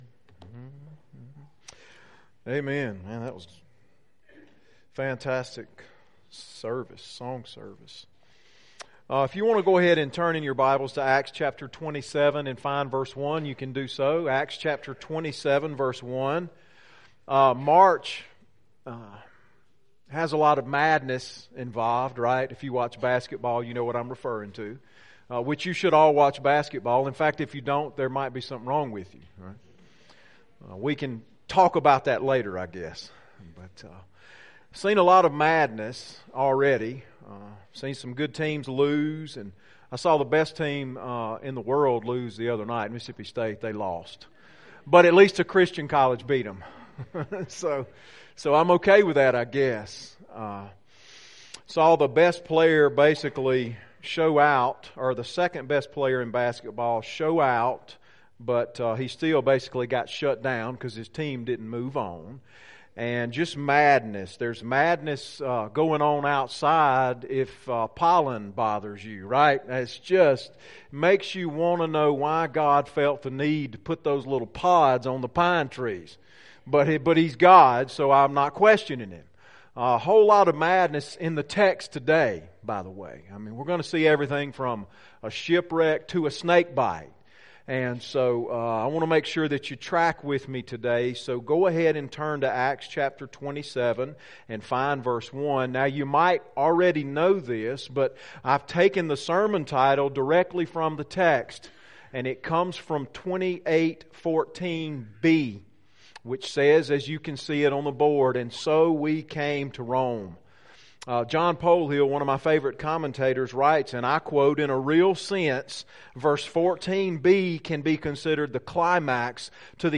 sermon-3-24-19-pc.mp3